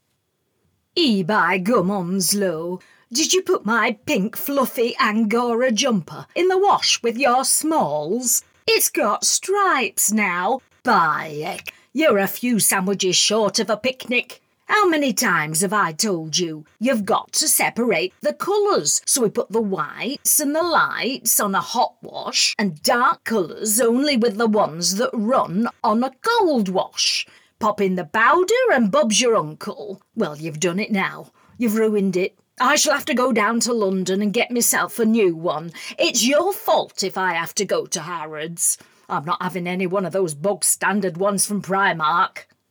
Cartoon & Character Voice Overs | The Voice Realm
1201Character_comedy_Northern.mp3